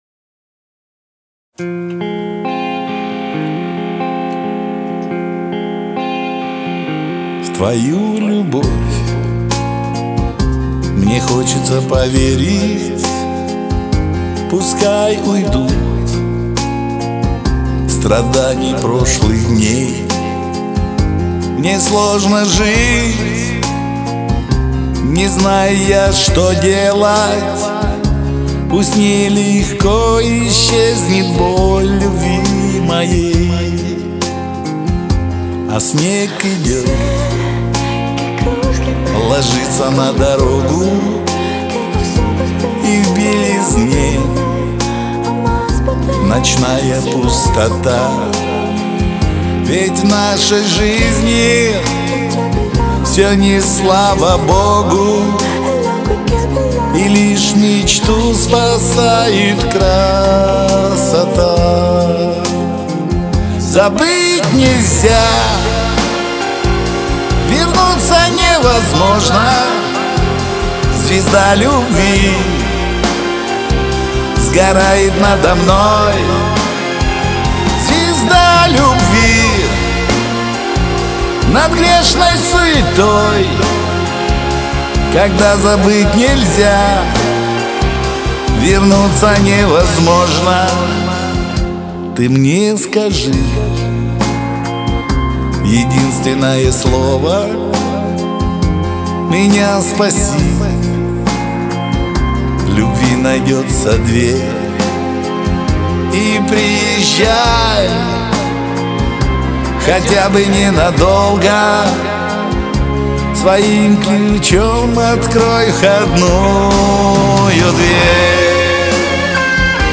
Вам бы её на полтончика ниже и была бы конфетка!